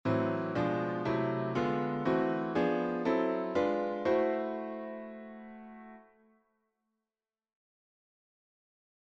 Improvisation Piano Jazz
Accord im6